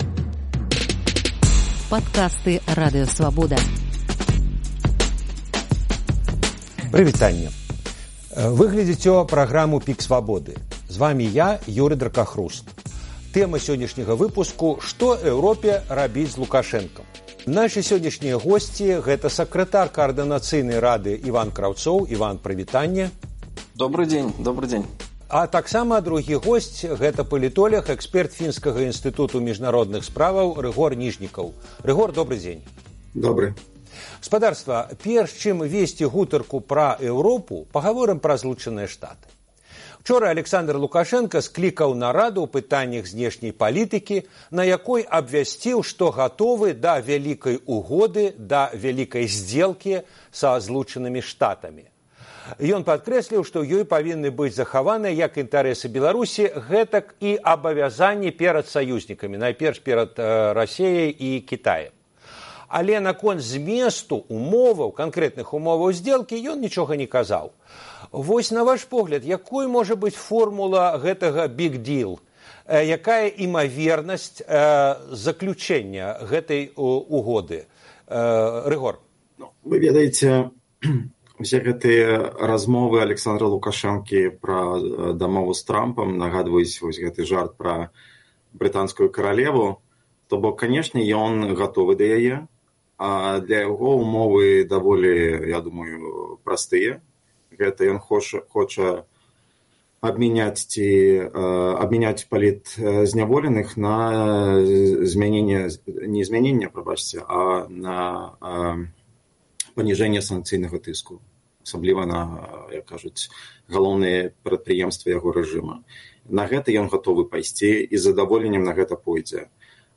Спрэчка